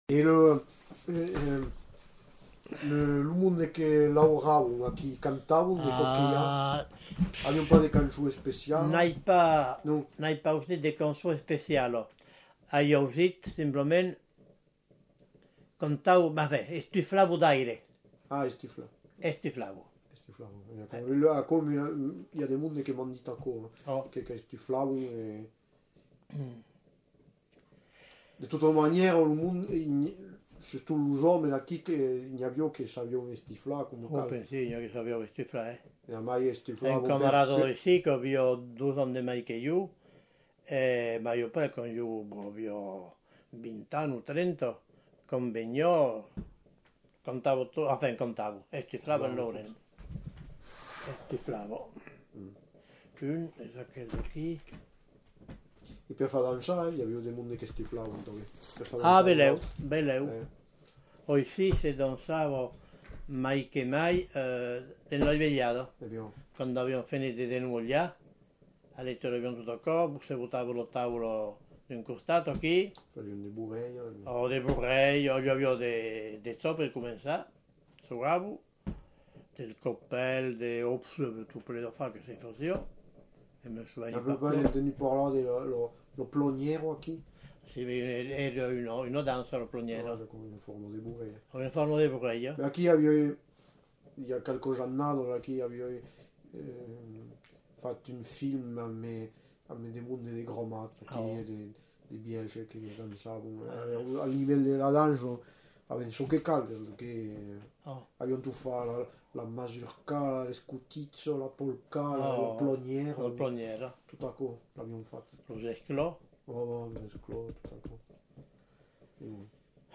Lieu : Rocamadour
Genre : témoignage thématique
Descripteurs : veillée ; fabrication d'instruments végétaux Instrument de musique : fifre ; sifflet végétal